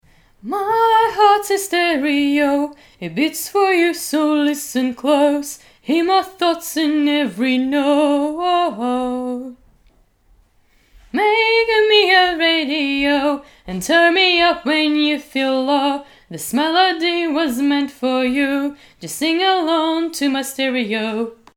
Качество неплохое, не цыкает и лишнего не особо с комнаты берёт.
Macbook Pro+Blue Yeti. Raw.